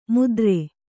♪ mudre